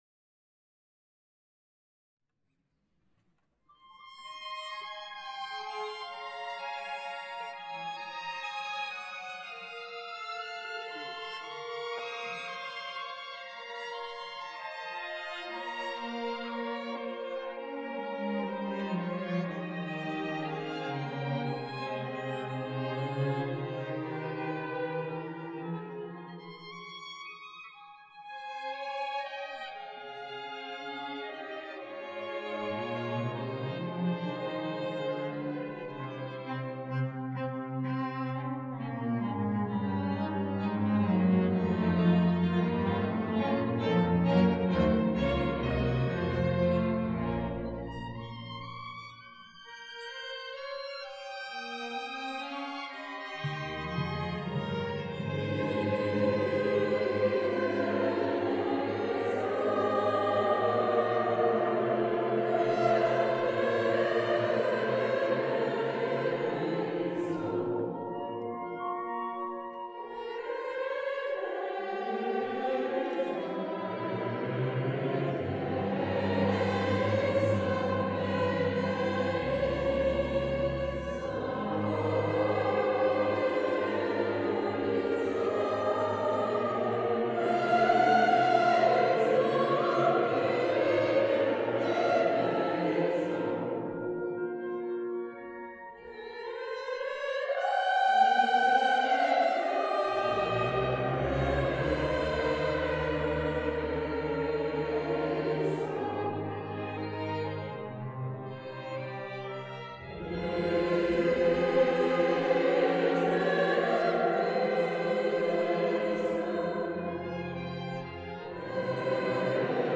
À 15h00, le grand concert classique démarrait devant des spectateurs nombreux et attentifs parmi lesquels étaient présents les élus toulonnais – Mme Geneviève Levy, adjointe à Mme le Maire et M. Guy Reynaud, conseiller Municipal – Demandez le programme !
Ci dessous la version complète en audio du Concerto pour clarinette.